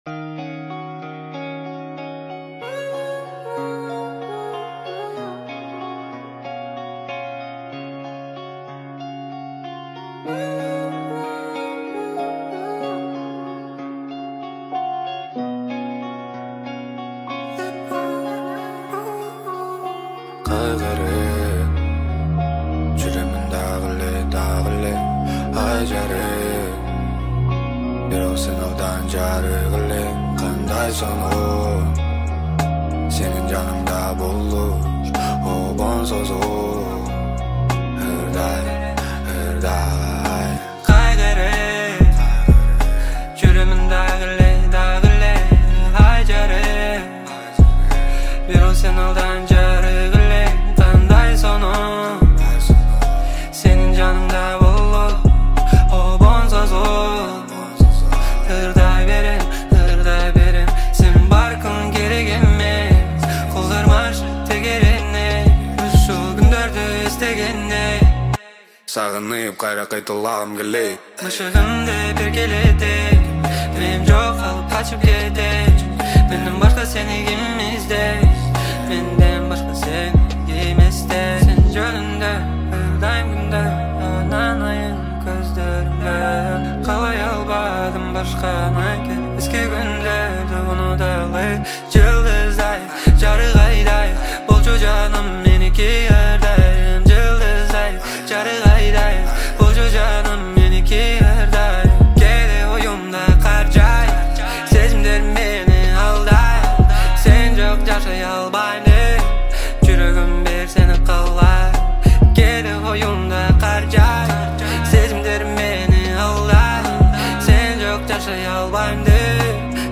киргизская музыка